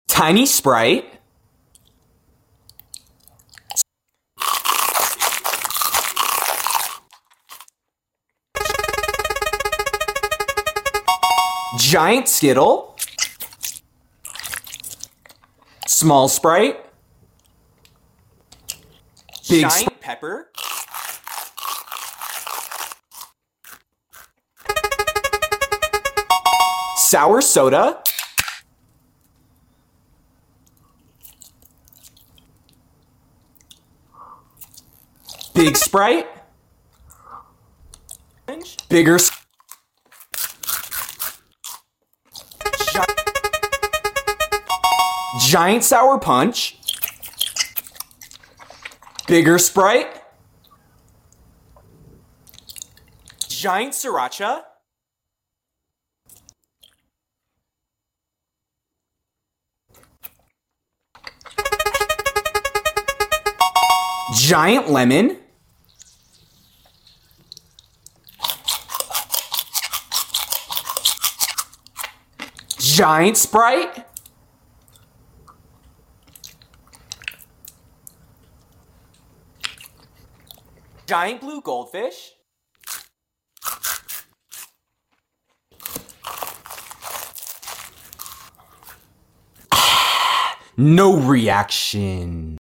Small and Giant Sprite Mp3 Sound Effect Tiny, Small and Giant Sprite ASMR!